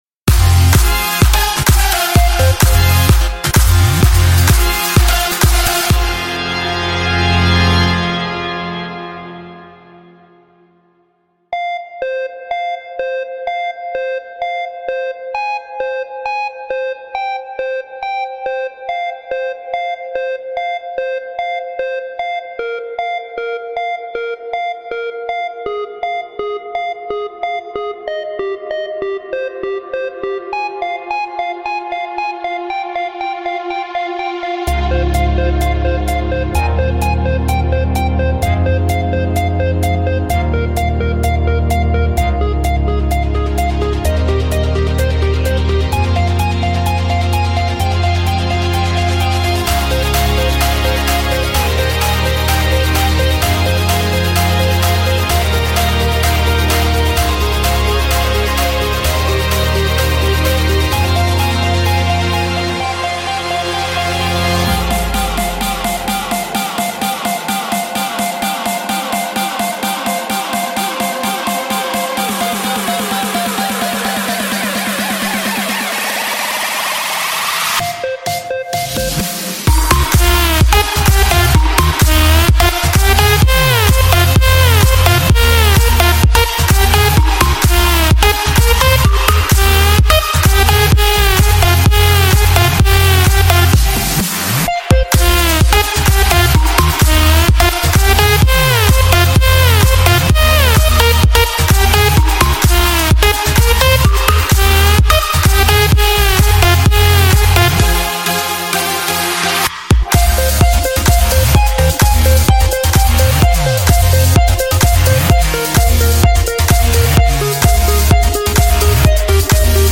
BPM61-128
Audio QualityPerfect (Low Quality)